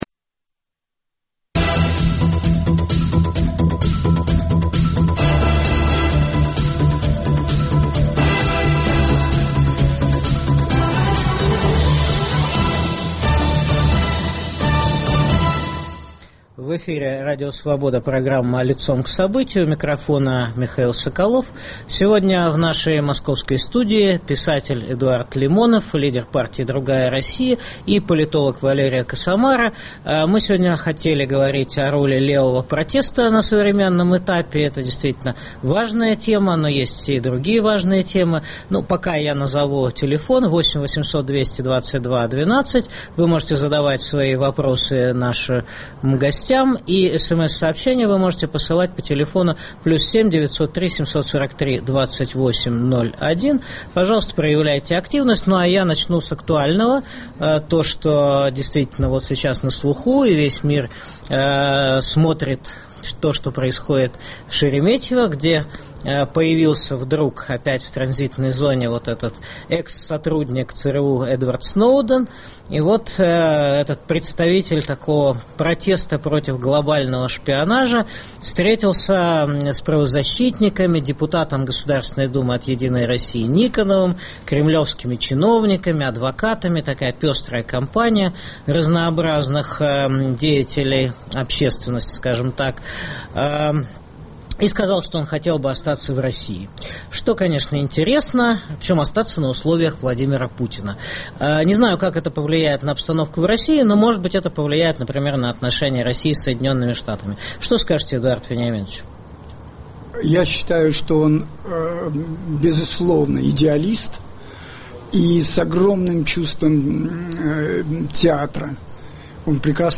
Почему Эдуард Лимонов борется не с Кремлем, а с либералами? Зачем левым радикалам рвать с «Болотной площадью». В программе о роли левого протеста в борьбе с режимом Владимира Путина дискутируют лидер «Другой России» Эдуард Лимонов